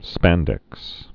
(spăndĕks)